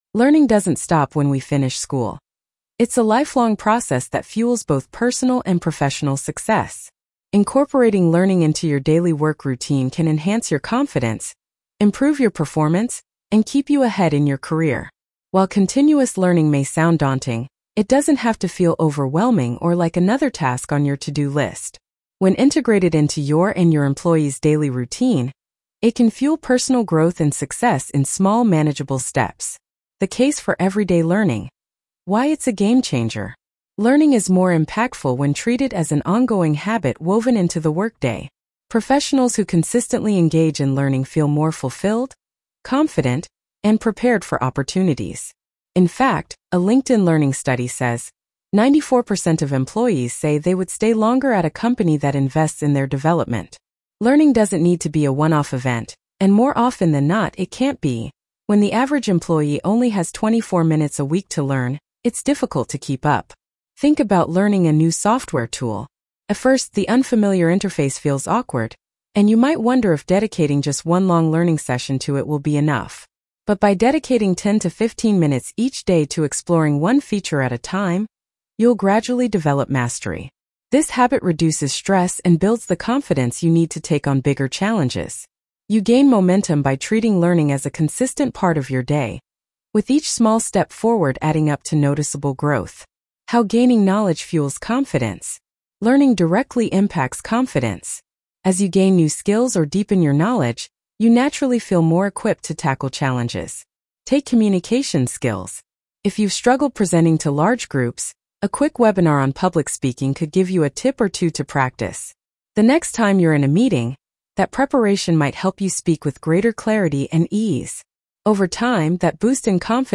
The Workday Just Got Smarter Blog Narration.mp3